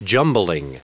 Prononciation du mot jumbling en anglais (fichier audio)
Prononciation du mot : jumbling